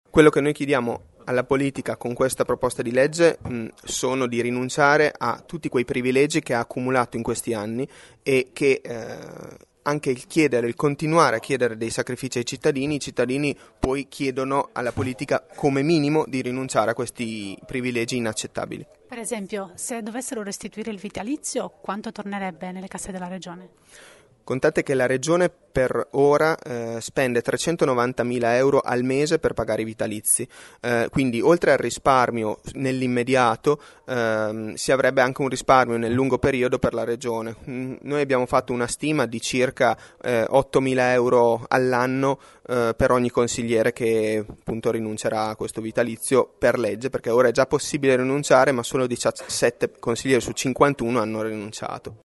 Fra gli ideatori dell’iniziativa c’è anche un consigliere comunale di Reggio che ne spiega così il senso